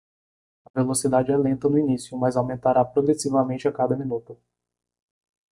Pronounced as (IPA)
/pɾo.ɡɾeˌsi.vaˈmẽ.t͡ʃi/